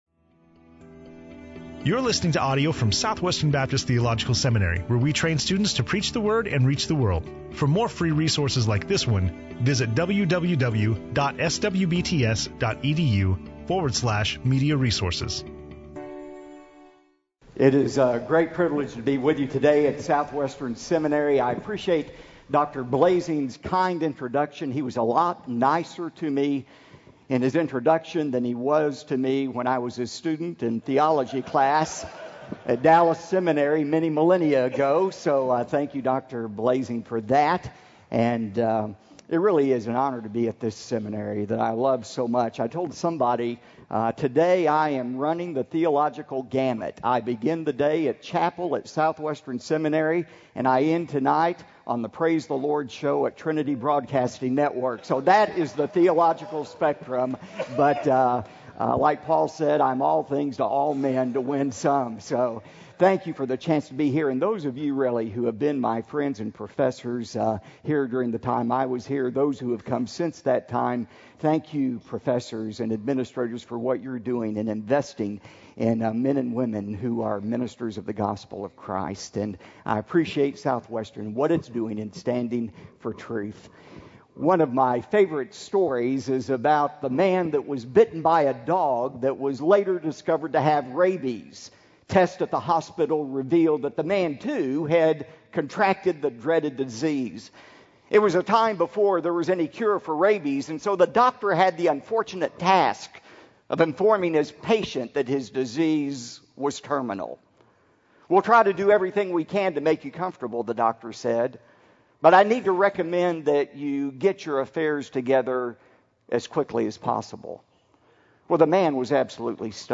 Dr. Robert Jeffress speaking on Matthew 18:21-35 in SWBTS Chapel on Tuesday August 28, 2012
SWBTS Chapel Sermons Robert Jeffress - SWBTS Chapel - August 28, 2012 Play Episode Pause Episode Mute/Unmute Episode Rewind 10 Seconds 1x Fast Forward 30 seconds 00:00 / Subscribe Share RSS Feed Share Link Embed